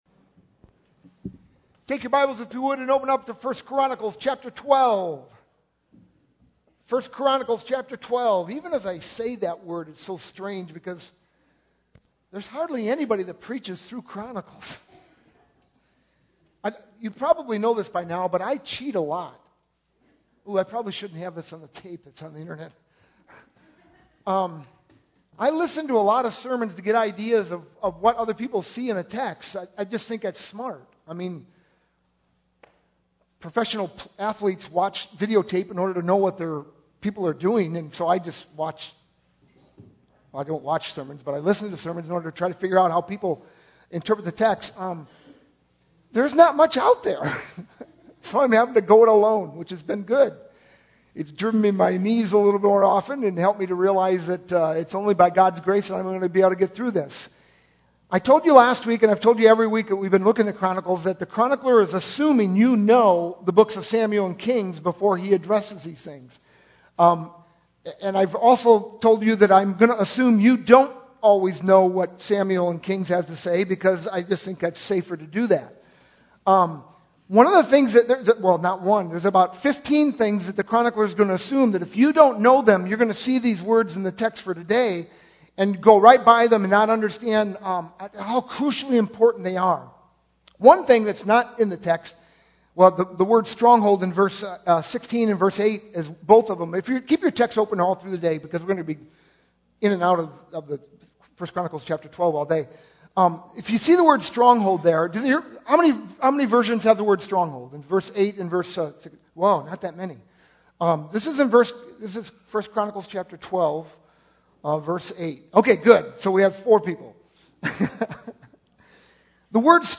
sermon-5-6-12.mp3